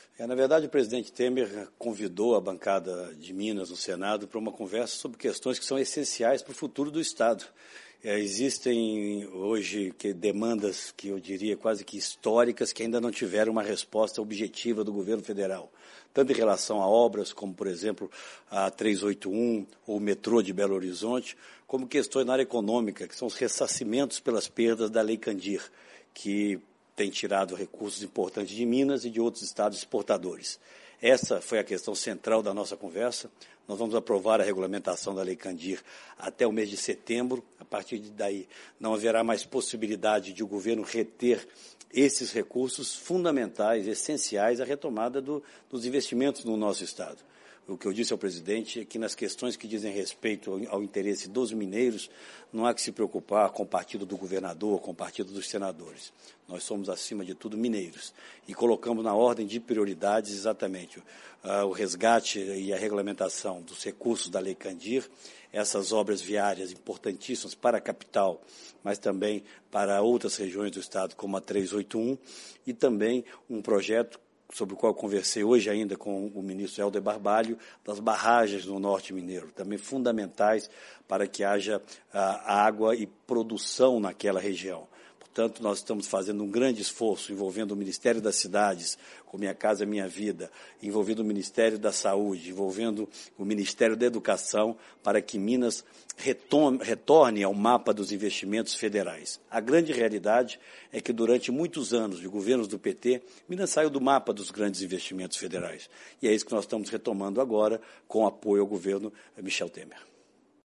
Entrevista do senador Aécio Neves – Brasília – 09-03-17